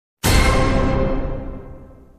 Tam! Meme Effect Sound sound effects free download